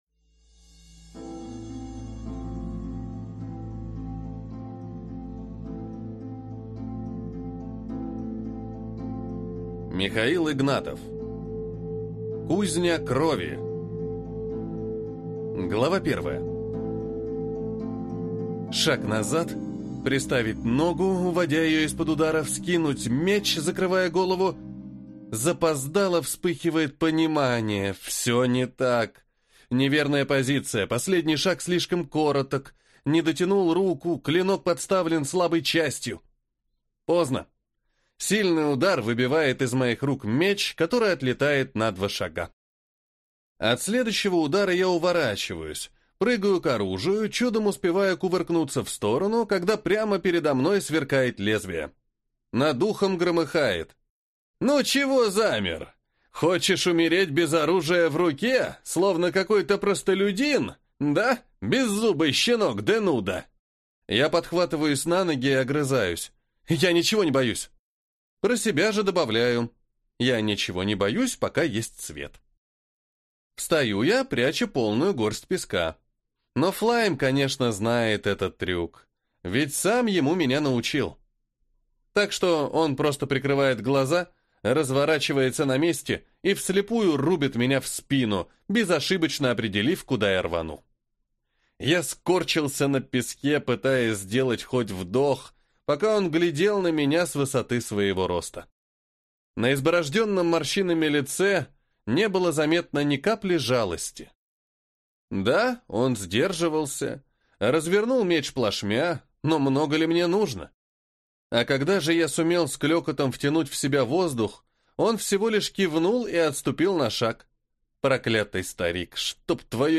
Аудиокнига Кузня крови | Библиотека аудиокниг
Прослушать и бесплатно скачать фрагмент аудиокниги